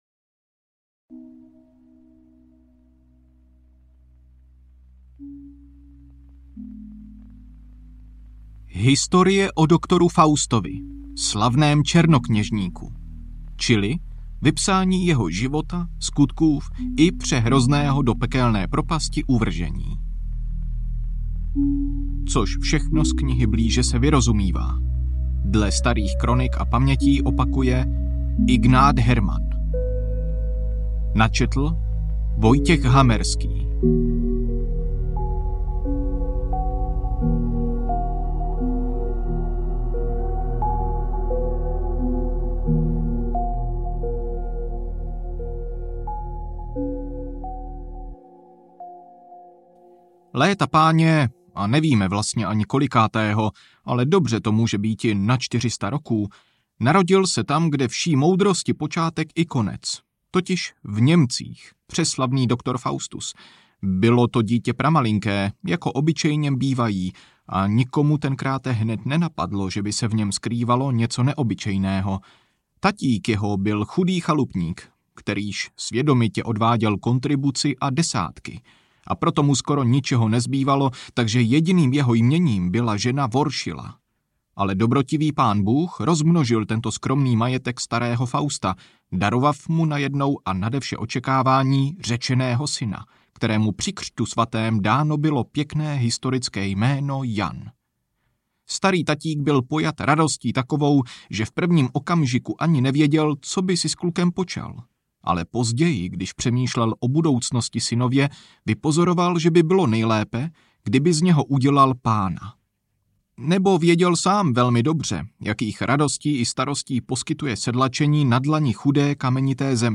Historie o doktoru Faustovi audiokniha
Ukázka z knihy